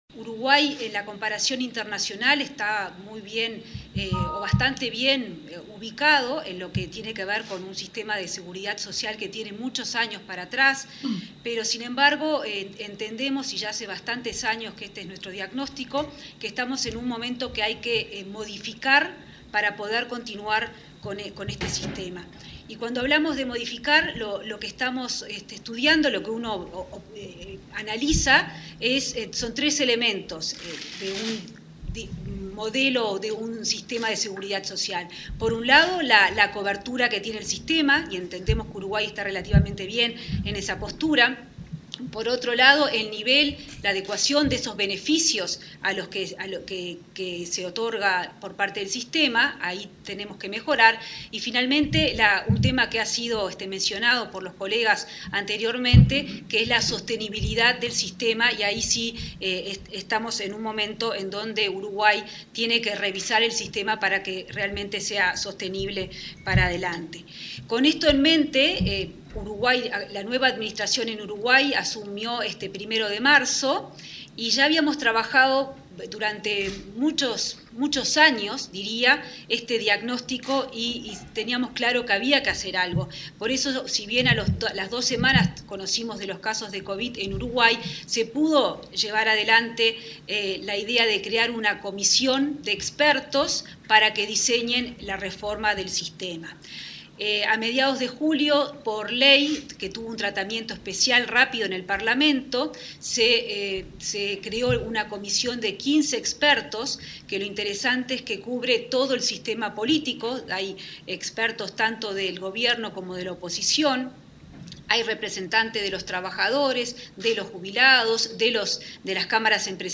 Lograr un sistema justo entre generaciones, solidario y financieramente viable es el pilar de la reforma previsional que impulsará el Gobierno, manifestó la ministra de Economía y Finanzas, Azucena Arbeleche, durante una videoconferencia con autoridades y especialistas de CAF-Banco de Desarrollo. Adelantó que este jueves 5 se realizará la primera reunión del grupo de expertos que diagnosticará el sistema en 90 días.
arbeleche.mp3